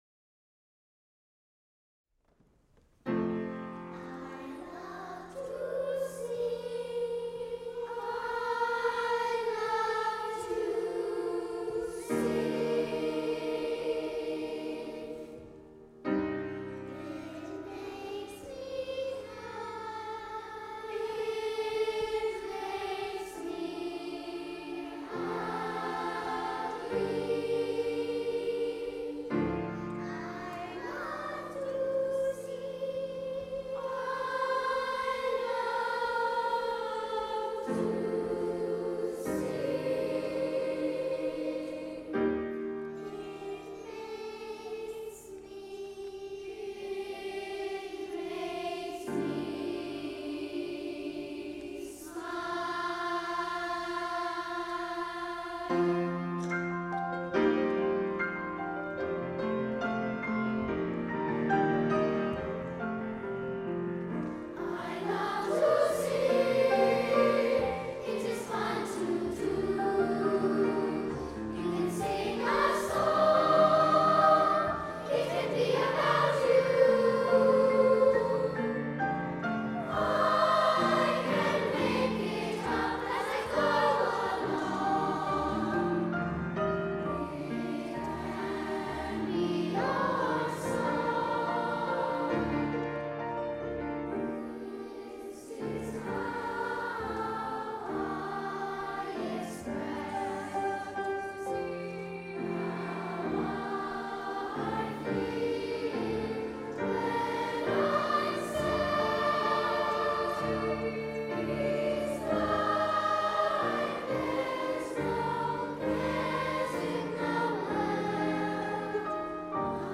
Download the score Download the synthesized mp3
A choral work
Instrumentation: SSSA Piano Composition Date: August 2015 Download the score Download the synthesized mp3